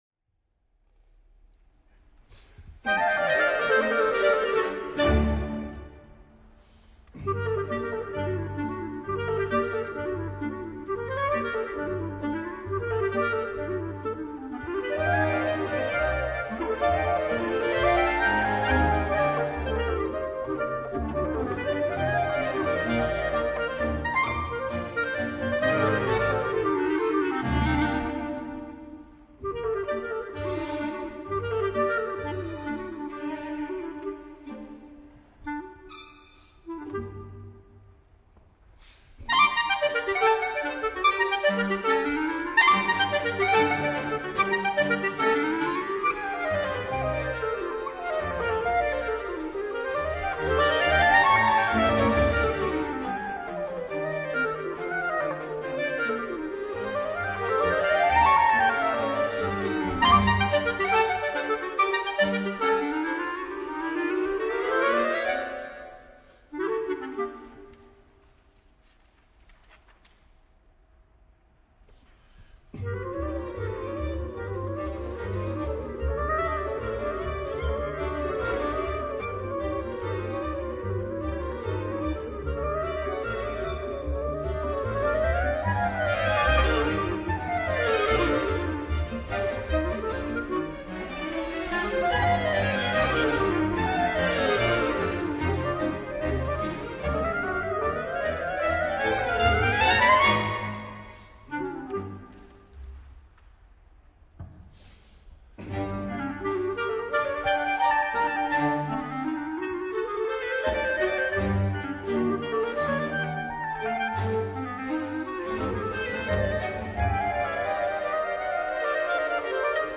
Disponibile anche per 2 clarinetti e orchestra d’archi
eseguita da due grandi virtuosi del clarinetto